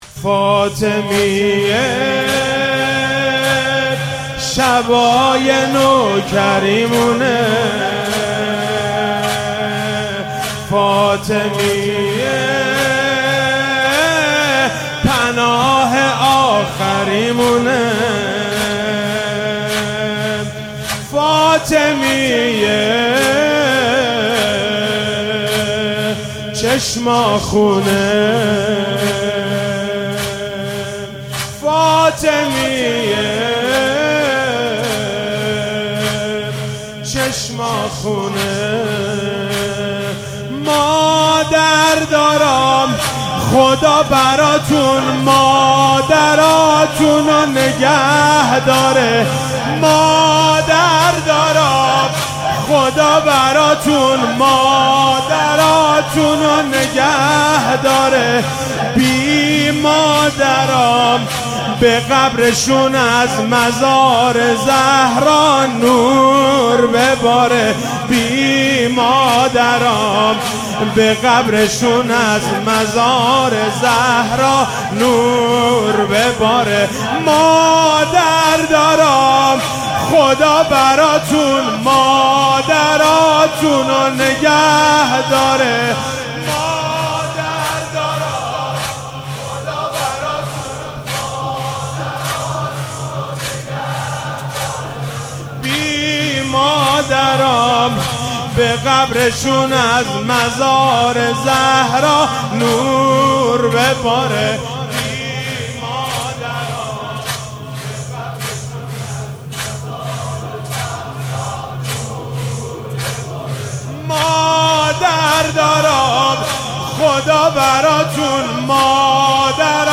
شب 2 فاطمیه 95 - زمینه - فاطمیه شبای نوکریمونه فاطمیه